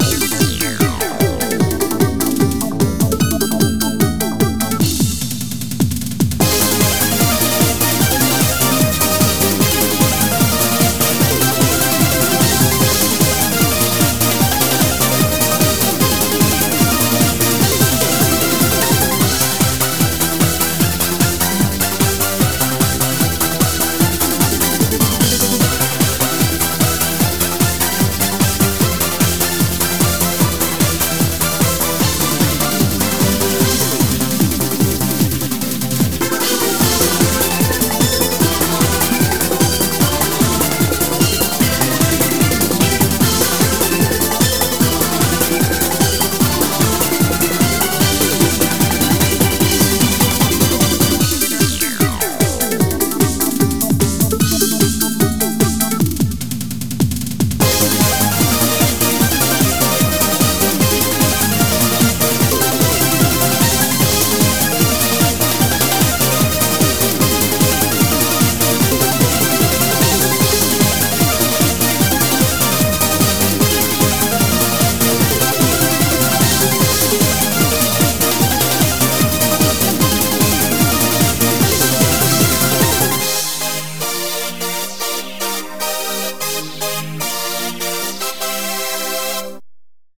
BPM150
Audio QualityPerfect (High Quality)
Better quality audio.